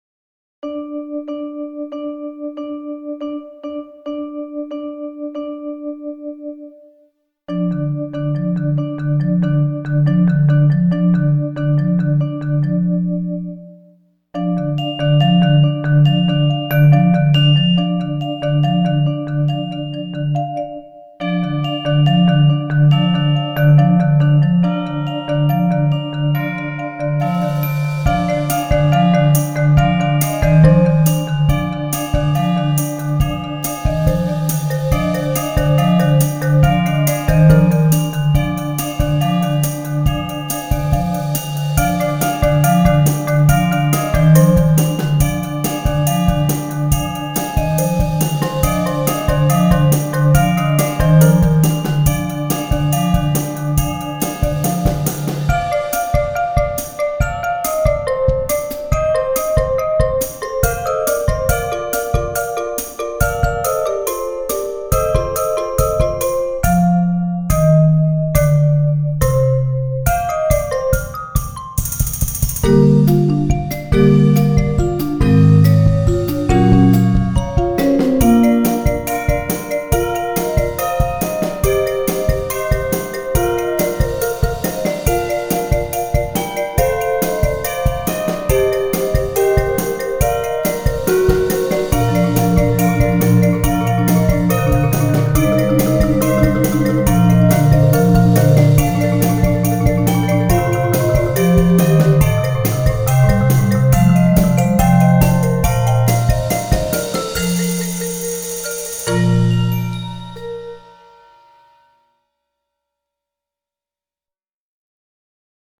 Voicing: Percussion Decet